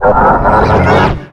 Cri de Hoopa Déchaîné dans Pokémon Rubis Oméga et Saphir Alpha.
Cri_0720_Déchaîné_ROSA.ogg